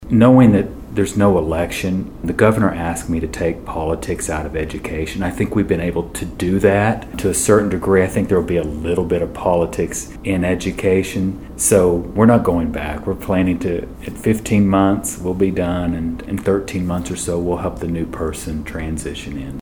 In a sit-down interview with Bartlesville Radio, Fields reflected on his first weeks in office and shared his vision for the future of Oklahoma education.
Lindel Fields on Not Running for Office 11-14.mp3